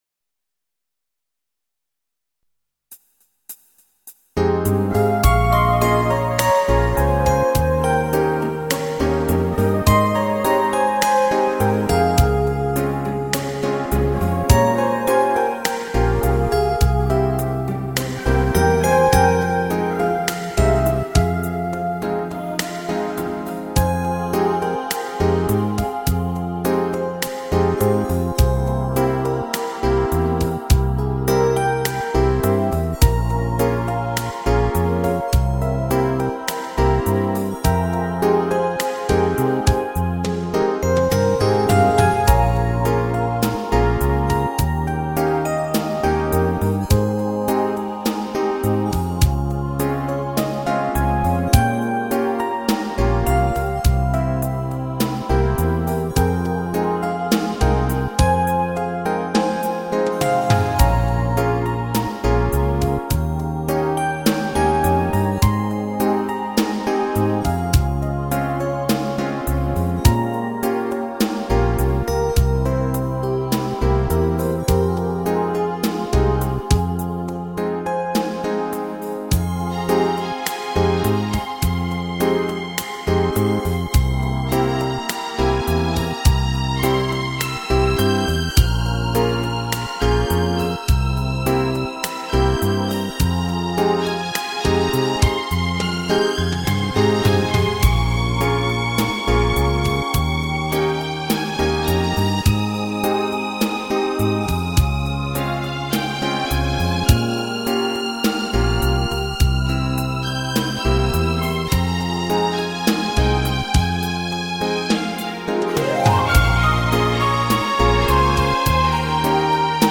Теги: фонограмма